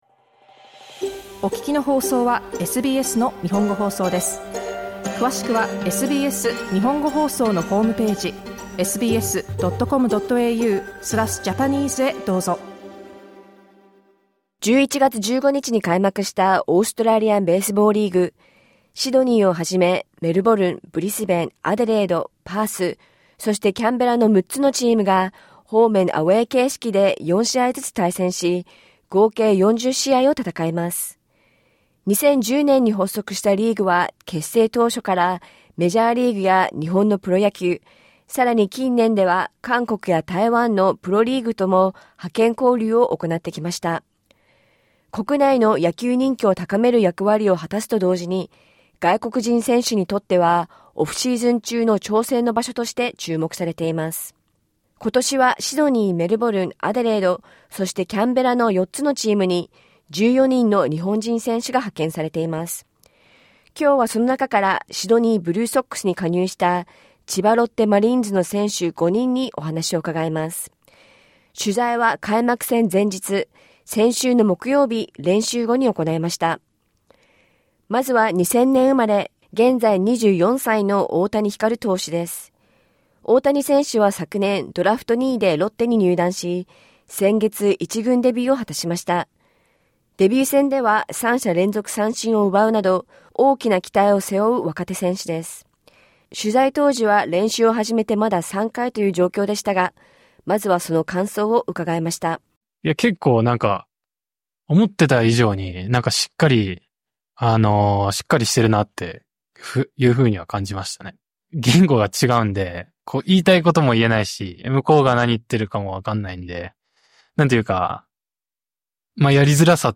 フルインタビューはポッドキャストから。